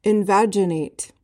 PRONUNCIATION:
(in-VAJ-uh-nayt)